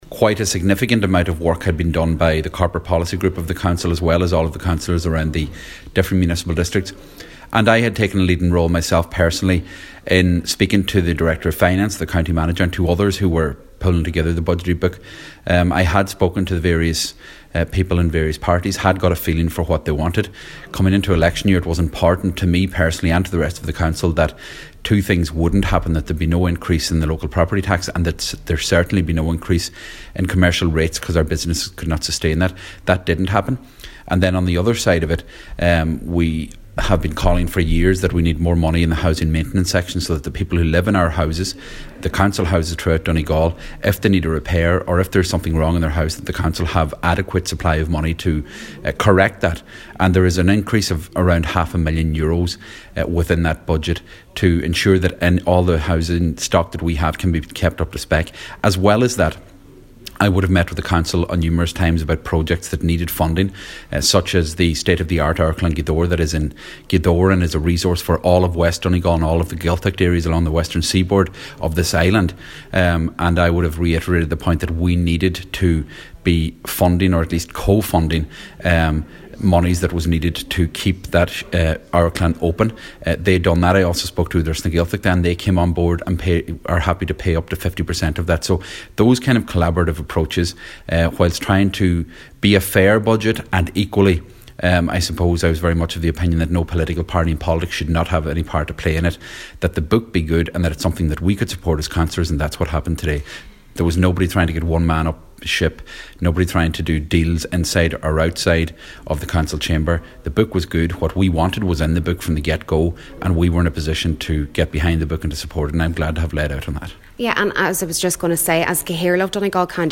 Speaking shortly after it was passed last night, Cllr Seamus O’Domhnaill says the book was good from the get go which ensured a very seamless day: